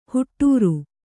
♪ huṭṭūru